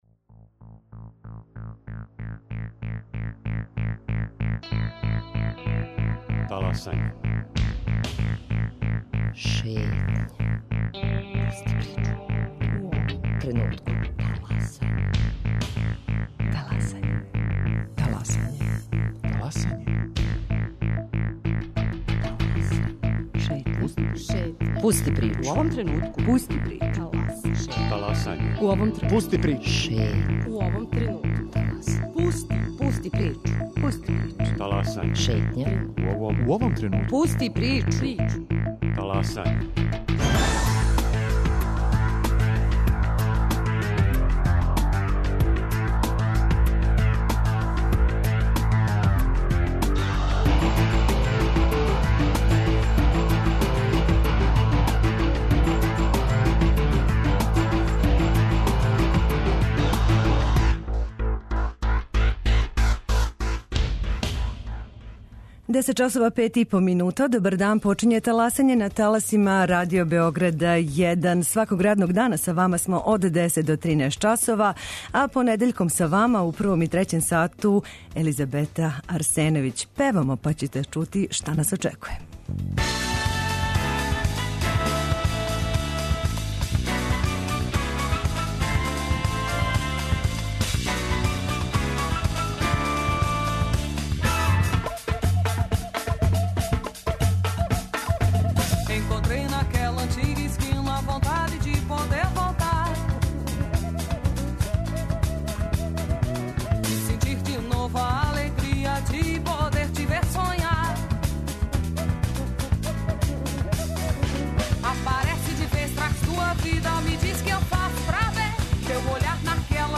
Радио Београд 1, 10.05